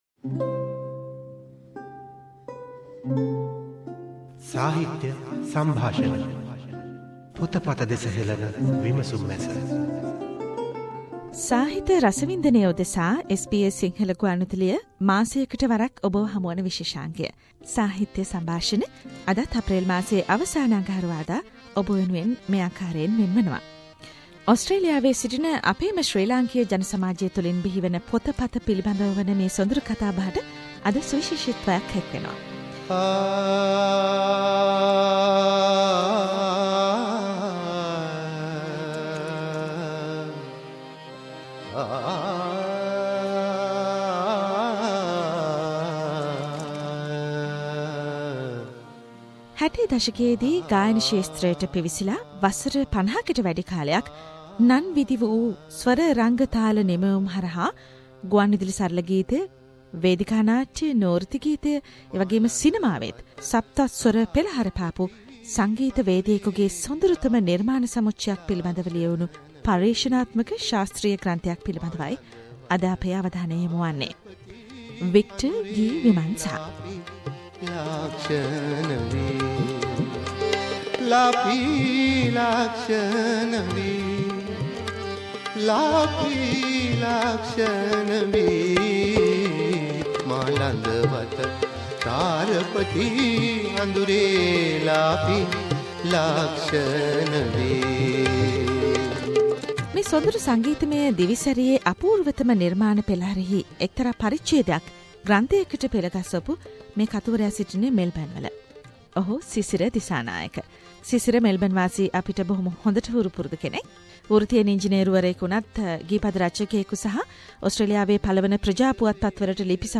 SBS Studios